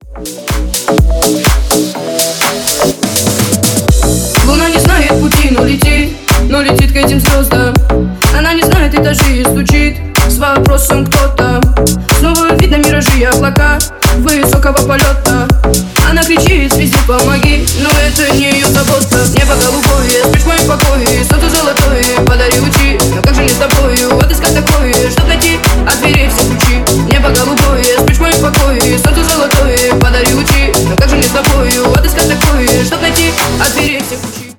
Ремикс
ритмичные